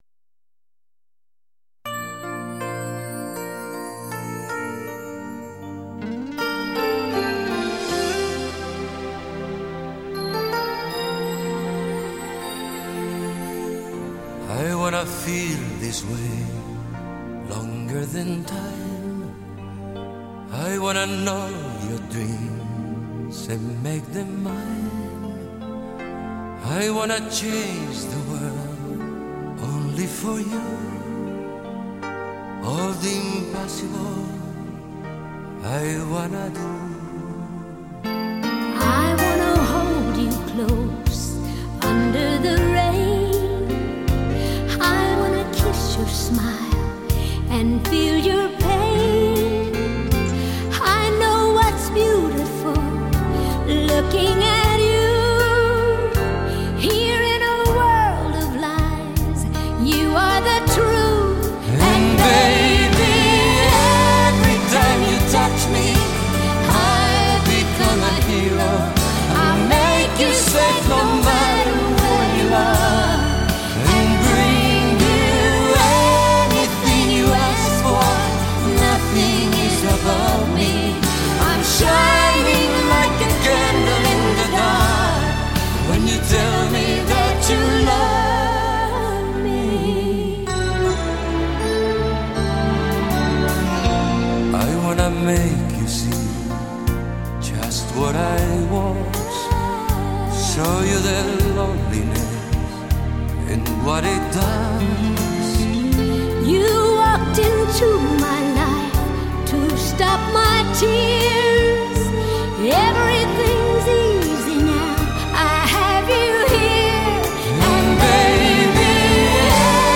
18首动人情歌
18首合唱动人情歌